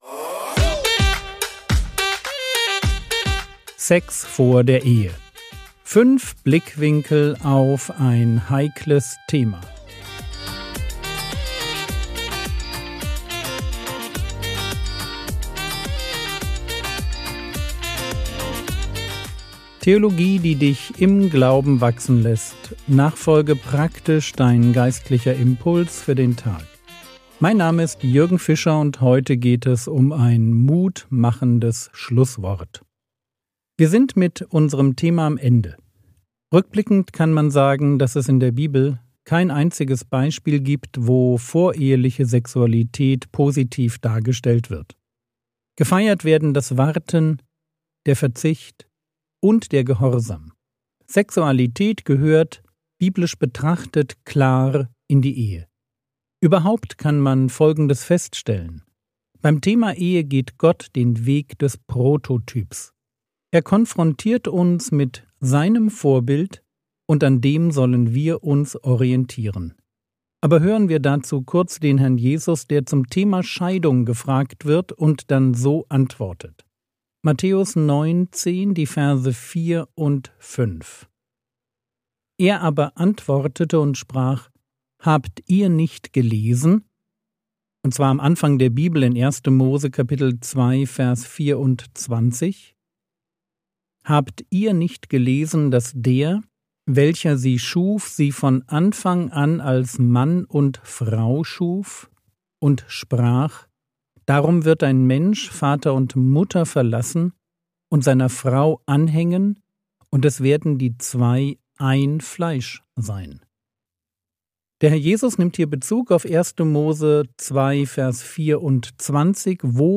Sex vor der Ehe (5/5) ~ Frogwords Mini-Predigt Podcast